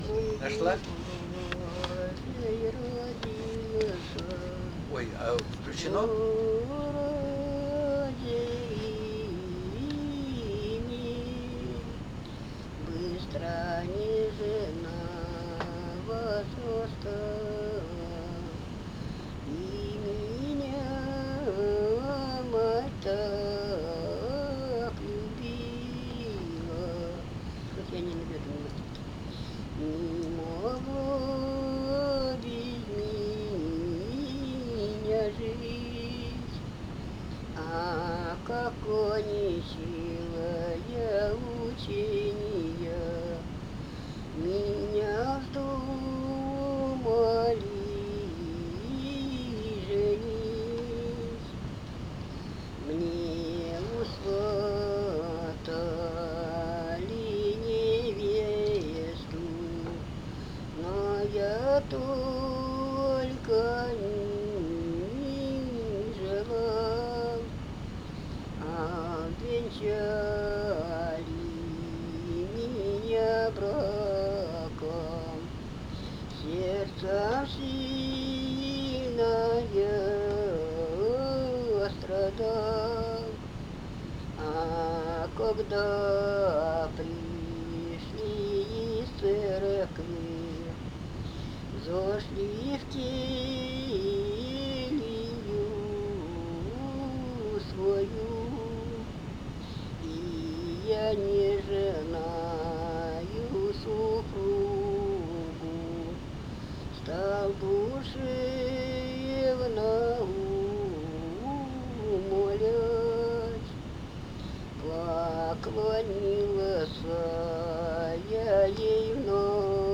В населенных пунктах Гороховецкого района в 2000–2001 годах записано с голоса и скопировано из рукописных тетрадей значительное число духовных стихов.
Религиозный стих